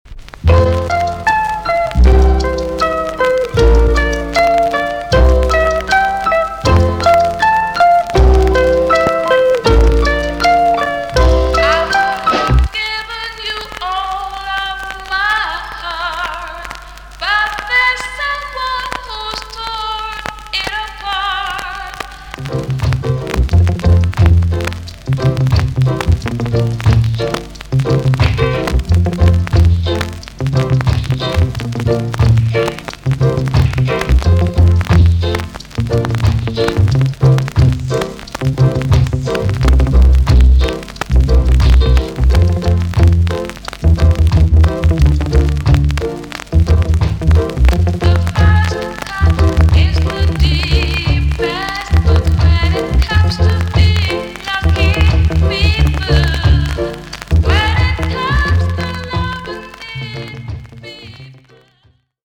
B.SIDE Version
VG ok チリノイズが入ります。